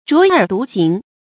卓爾獨行 注音： ㄓㄨㄛˊ ㄦˇ ㄉㄨˊ ㄒㄧㄥˊ 讀音讀法： 意思解釋： 謂超越眾人，不隨俗浮沉。